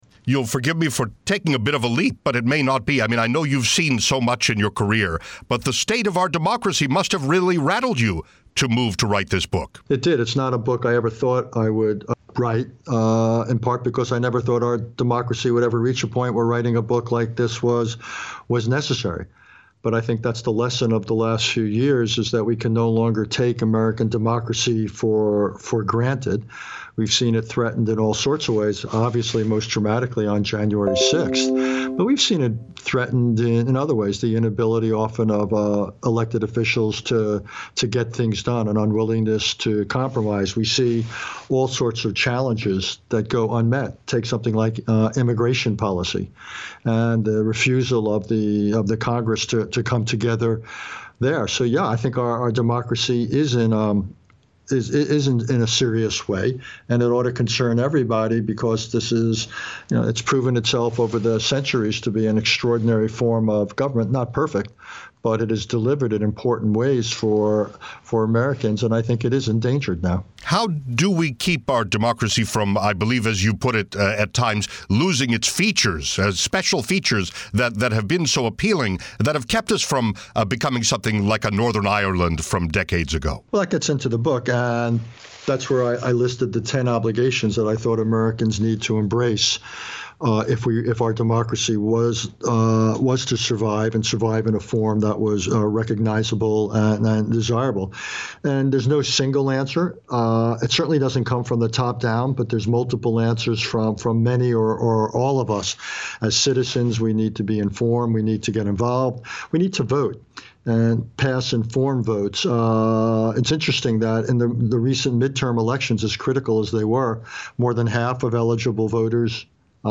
WTOP’s Interviews and Links